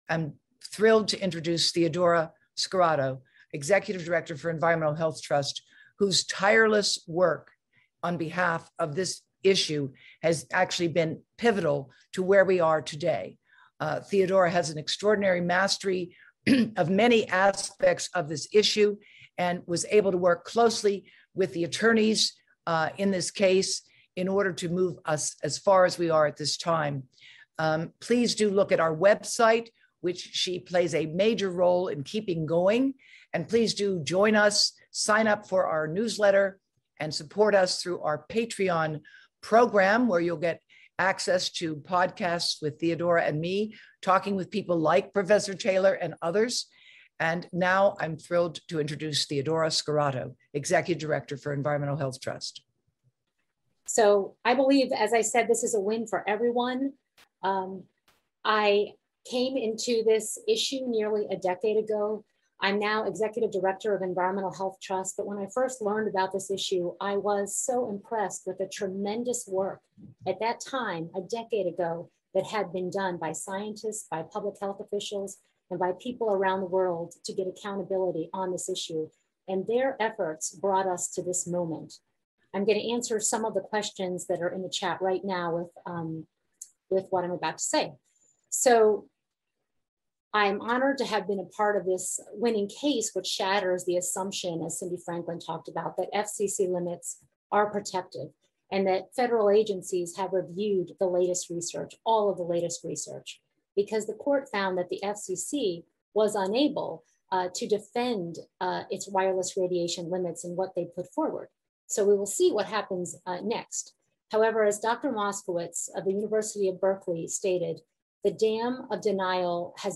Press Conference Excerpts